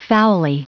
Prononciation du mot foully en anglais (fichier audio)
Prononciation du mot : foully